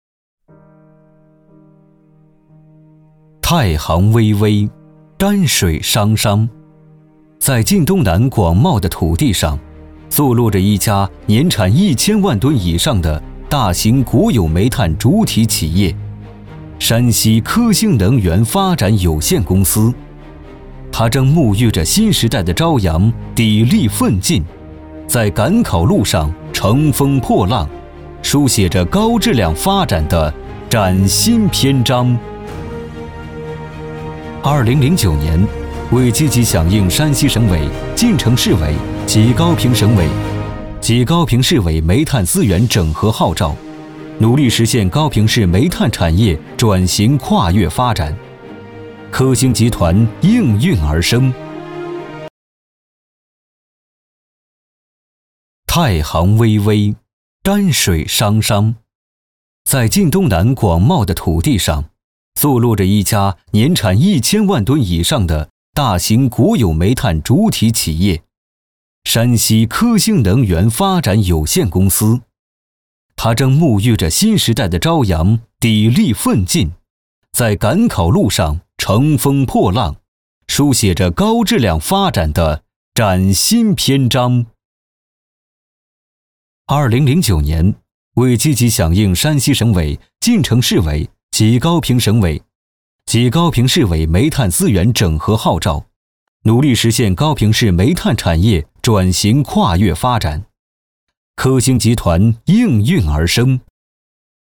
中文男声
• 宣传片
• 大气
• 沉稳